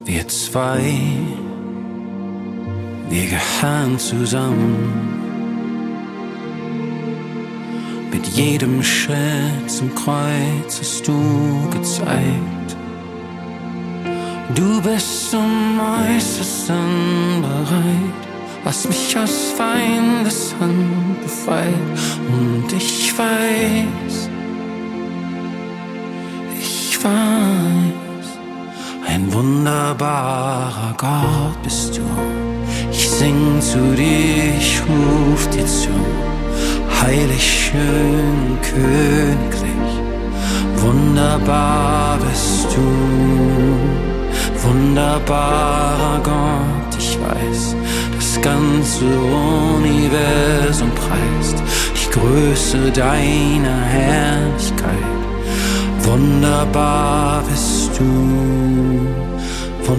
Lobpreis